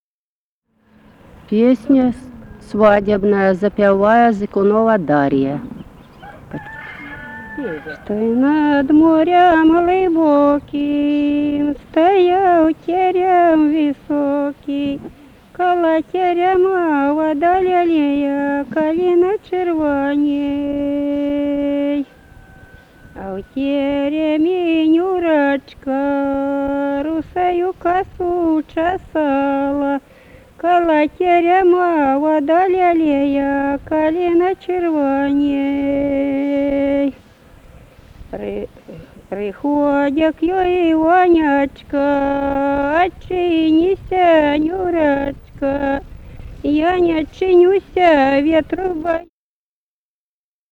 Музыкальный фольклор Климовского района 040. «Что й над морем глыбоким» (свадьбишная).